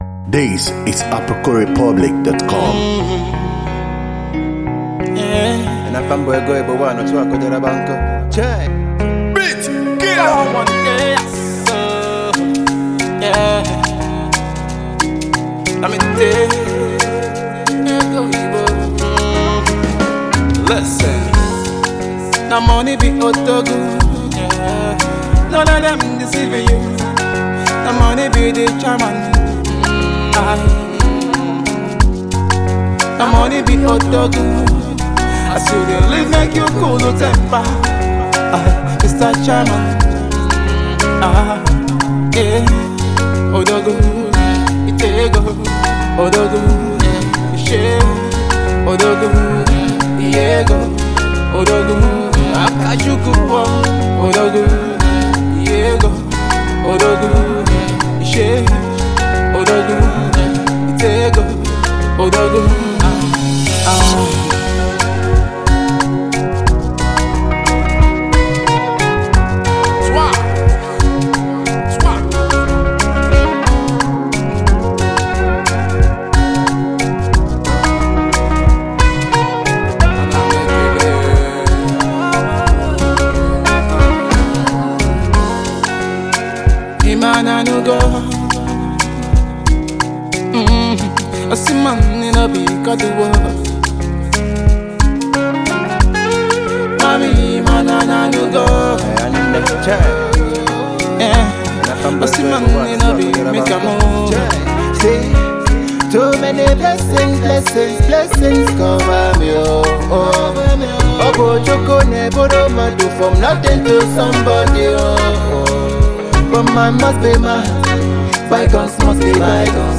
The music is a mix up of afro and pop.